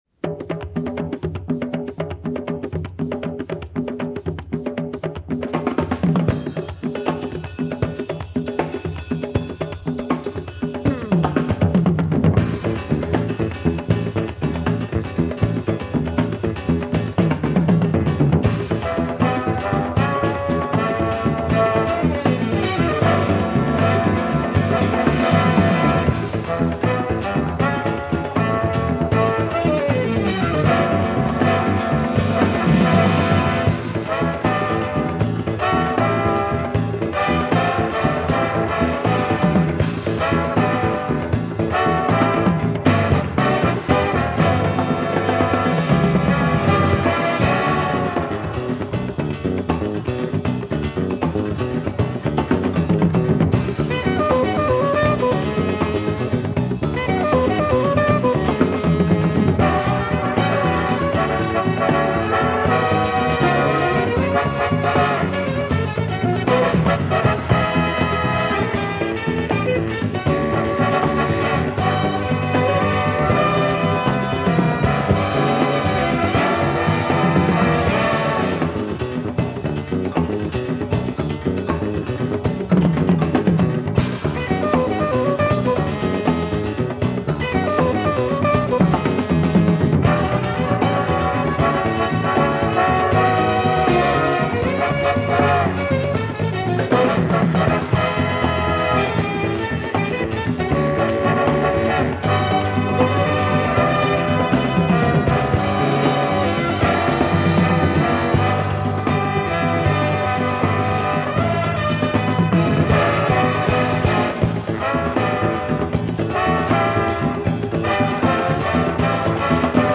Category: big band
Style: mapale (6/8)
Instrumentation: big band (4-4-5 rhythm (4)